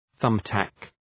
Προφορά
{‘ɵʌm,tæk}